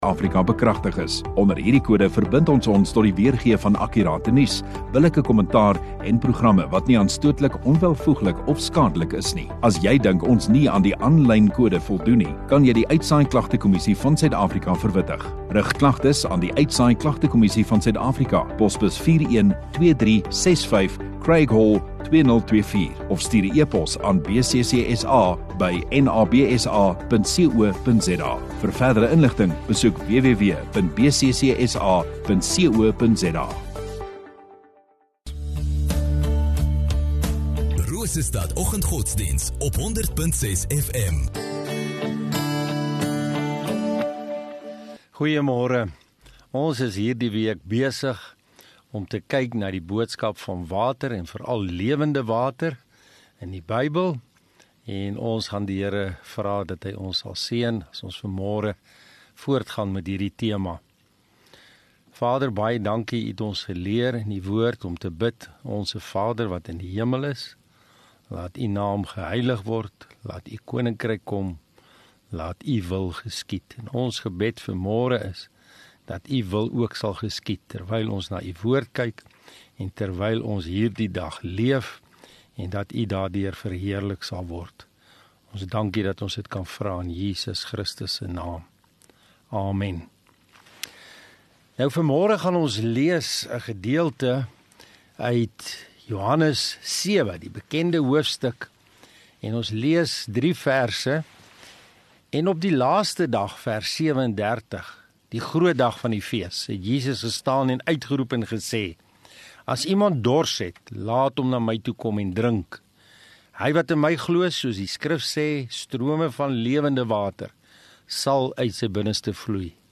10 Apr Vrydag Oggenddiens